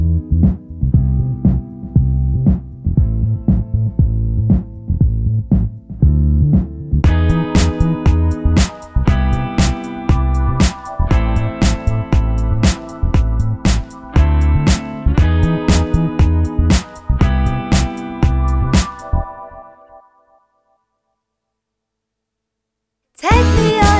Rap Section Removed R'n'B / Hip Hop 3:13 Buy £1.50